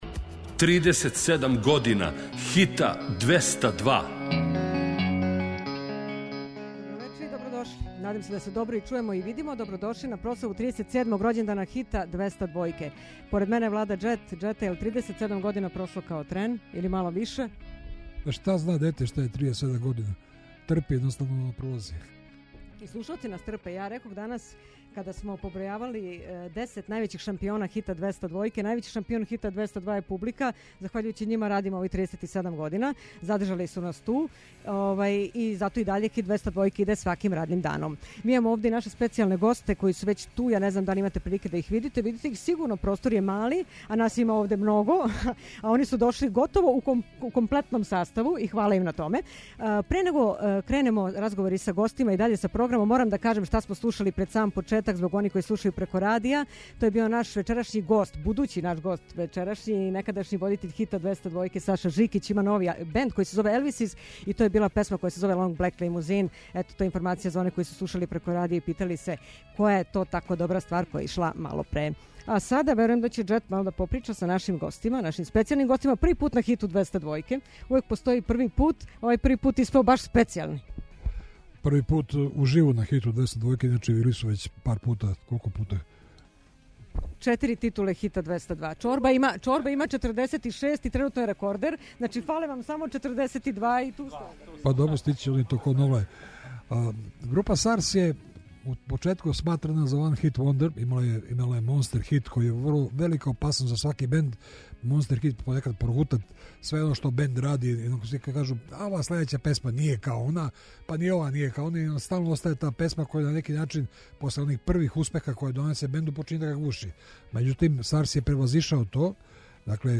Директним преносом и видео стримингом на сајту РТС и на званичном Јутјуб каналу Радио Београда, из Студија 21 преносимо обележавање годишњице емисије 'Хит недеље'.